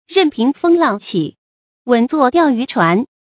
注音：ㄖㄣˋ ㄆㄧㄥˊ ㄈㄥ ㄌㄤˋ ㄑㄧˇ ，ㄨㄣˇ ㄗㄨㄛˋ ㄉㄧㄠˋ ㄧㄩˊ ㄔㄨㄢˊ